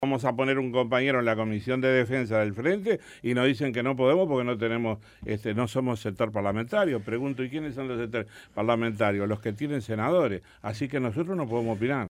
Escuche a Semproni